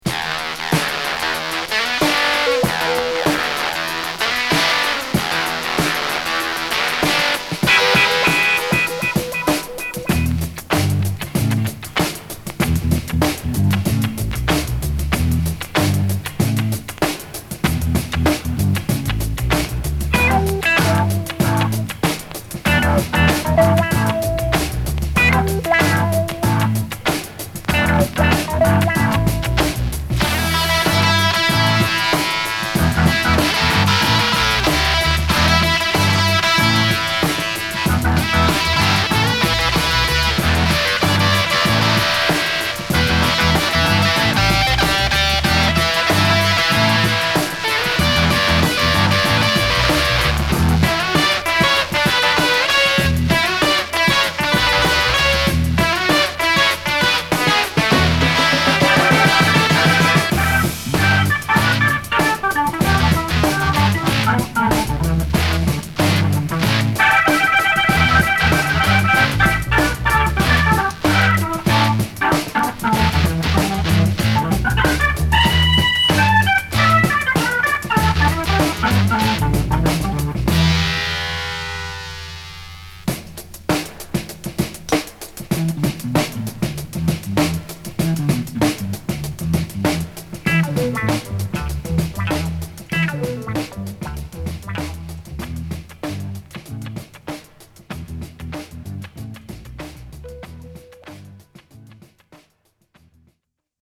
ファットなドラム&ベースの上で、サイケデリックなギターとファンキーなエレピが乗るキラーカヴァー！